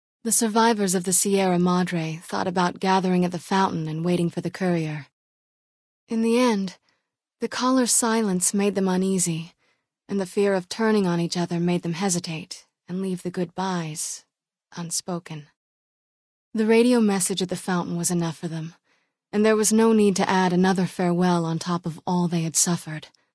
Category:Dead Money endgame narrations Du kannst diese Datei nicht überschreiben.